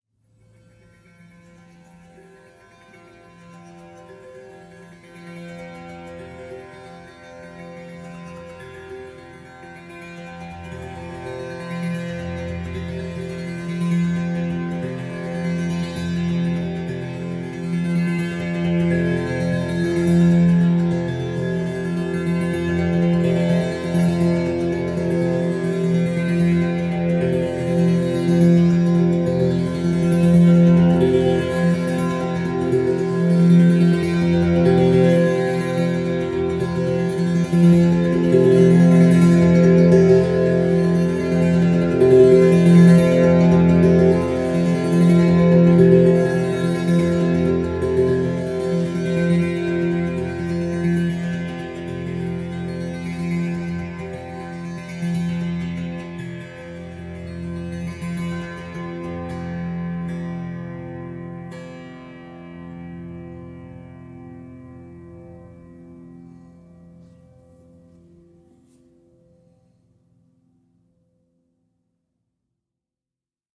Monolini F Monochord therapy sequence - Fm - 135.mp3
Original creative-commons licensed sounds for DJ's and music producers, recorded with high quality studio microphones.
monolini_f_monochord_therapy_sequence_-_fm_-_135_ygy.ogg